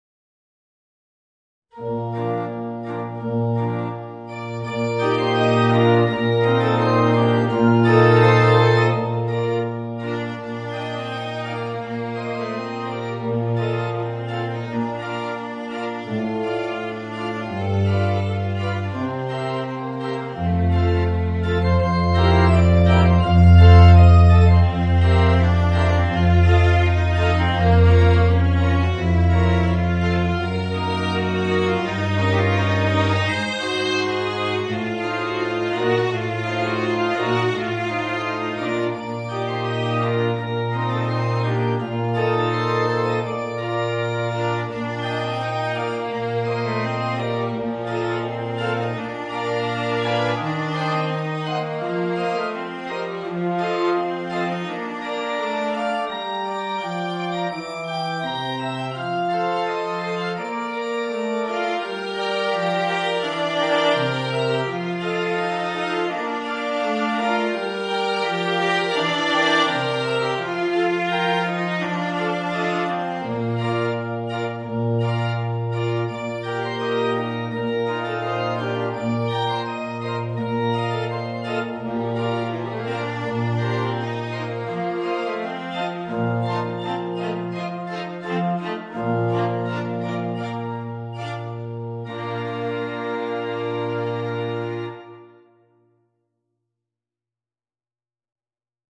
Voicing: Viola and Organ